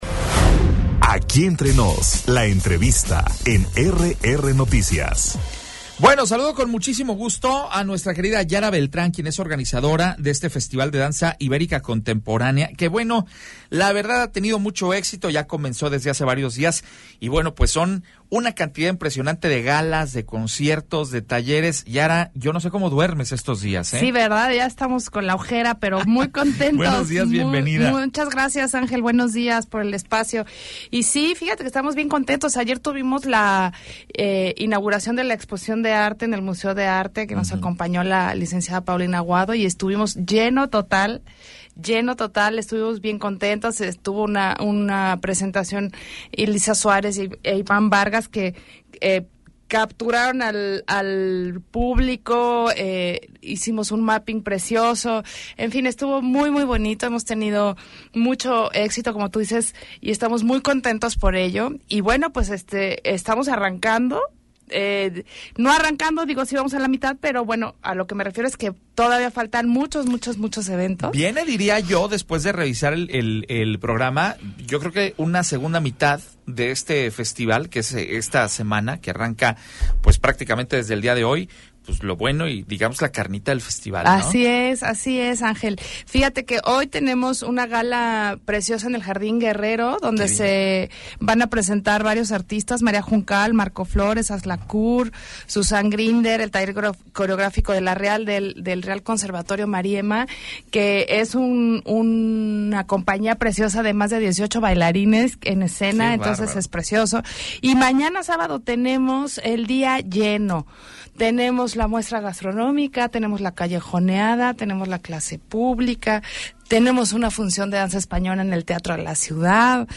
EntrevistasMultimediaPodcast